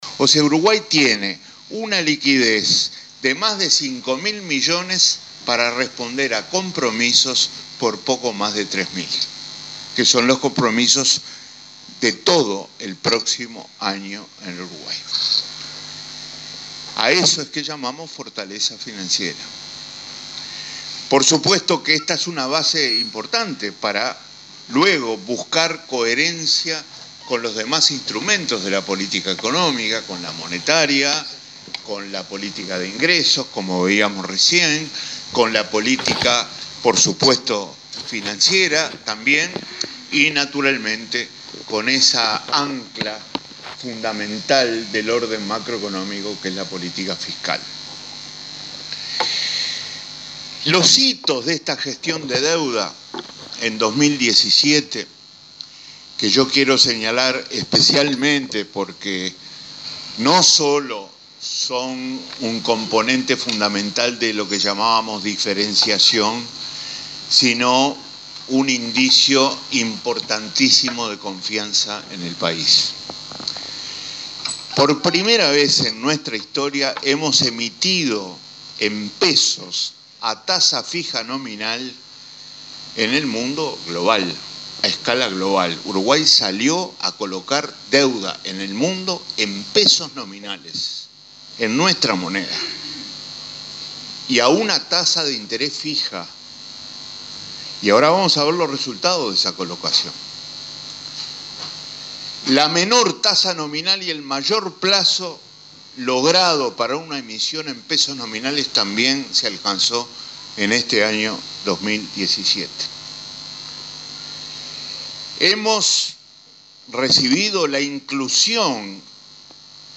Uruguay tiene liquidez por US$ 5.000 millones para cumplir compromisos por US$ 3.000 millones, que son los del año siguiente, “a eso le llamamos fortaleza financiera”, afirmó el ministro de Economía y Finanzas, Danilo Astori, en la inauguración de las Jornadas Académicas 2017. Destacó que las reservas internacionales superan los US$ 17.000 millones, o sea un 25 % del PBI.